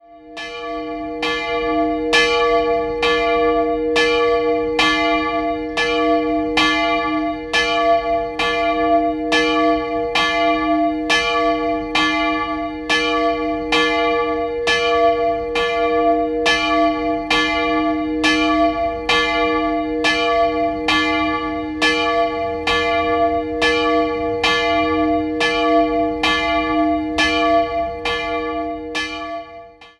Die Martin-Luther-Kirche wurde 1968 errichtet und gehört zur Regensburger Lukasgemeinde. Einzelglocke: d'' Die Glocke wurde vermutlich im 14. Jahrhundert gegossen und hing ursprünglich in der evangelischen Kirche von Edelsfeld in der Oberpfalz.